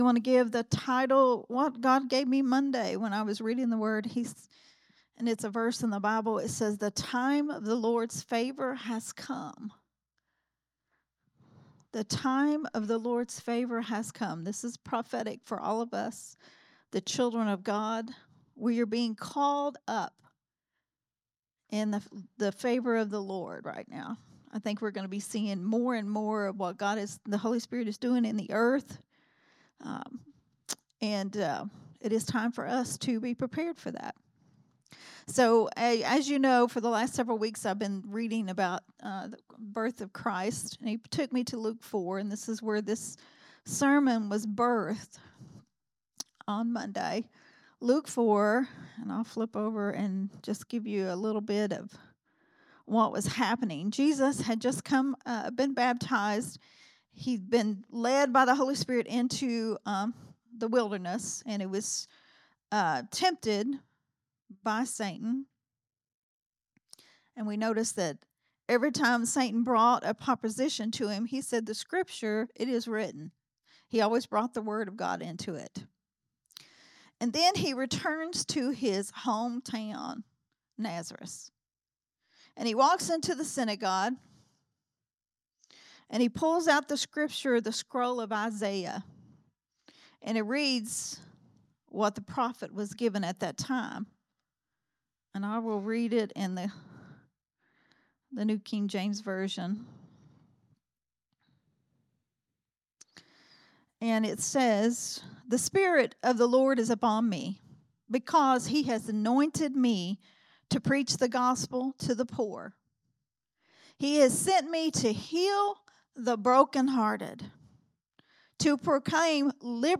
Sunday Morning Risen Life teaching
recorded at Growth Temple Ministries